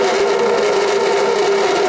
canister
scrape1.ogg